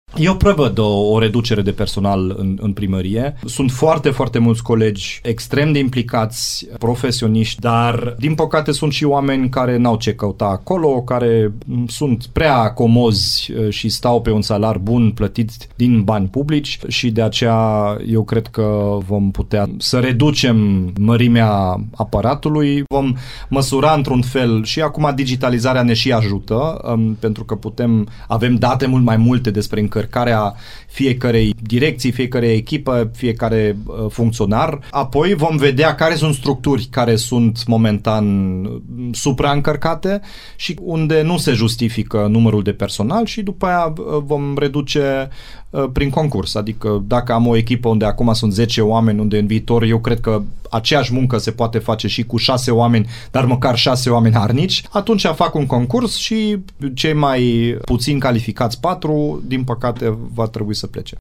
Primarul Dominic Fritz a declarat la Radio Timișoara că după aprobarea bugetului va ști ce economie este necesară la nivelul municipalității.